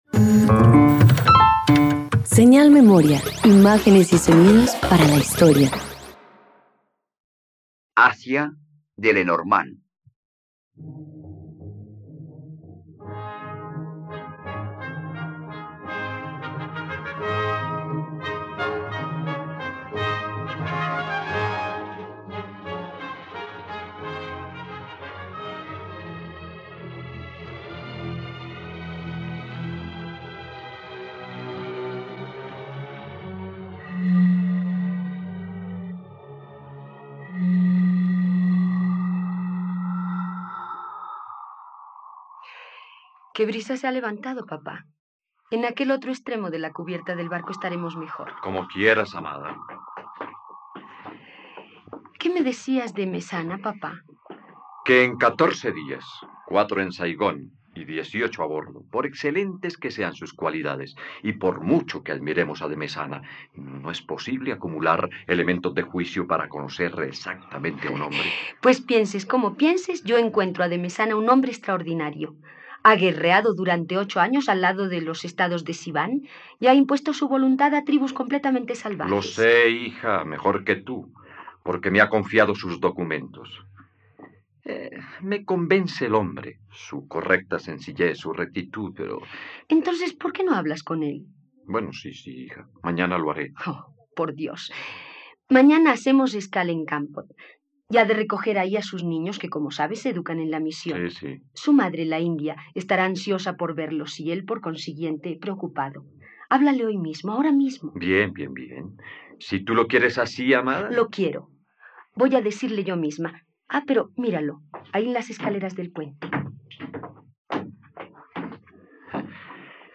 ..Radioteatro. Escucha la adaptación de la obra "Asia" del dramaturgo Henri-René Lenormand en la plataforma de streaming RTVCPlay.